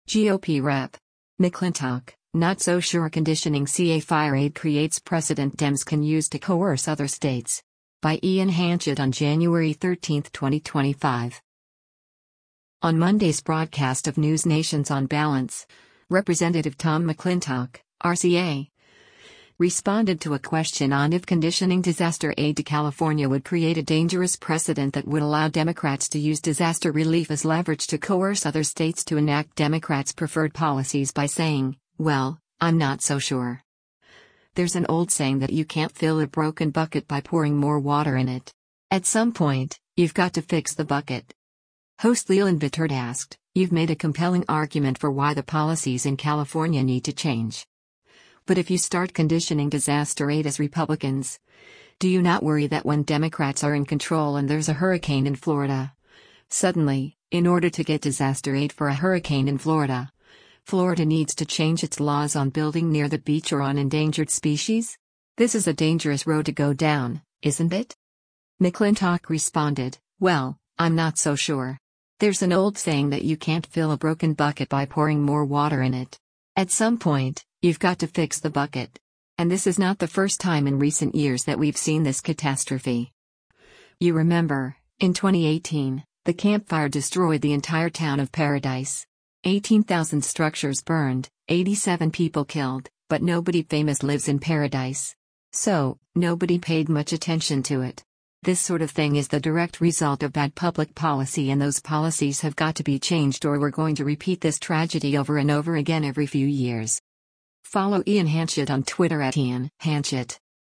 On Monday’s broadcast of NewsNation’s “On Balance,” Rep. Tom McClintock (R-CA) responded to a question on if conditioning disaster aid to California would create a dangerous precedent that would allow Democrats to use disaster relief as leverage to coerce other states to enact Democrats’ preferred policies by saying, “Well, I’m not so sure.
Host Leland Vittert asked, “You’ve made a compelling argument for why the policies in California need to change.